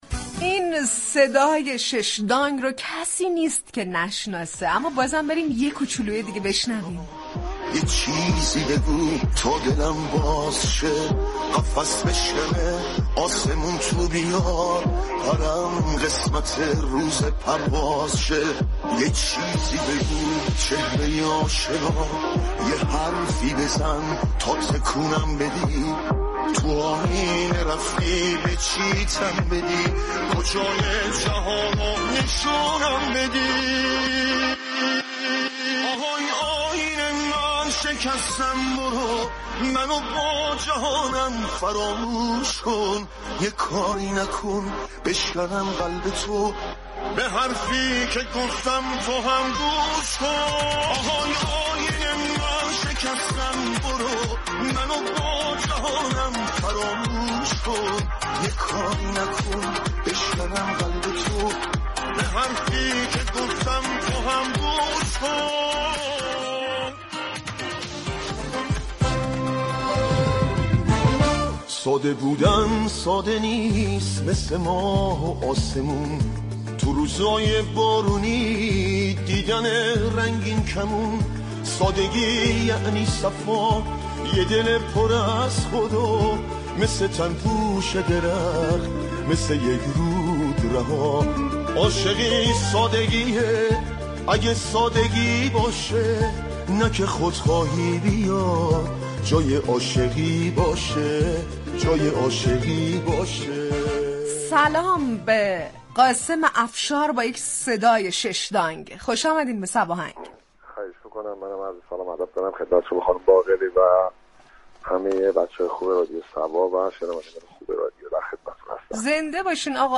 قاسم افشار در گفتگو تلفنی برنامه صباهنگ درباره راز ماندگاری آثار خوانندگان هم نسل خودش توضیح داد.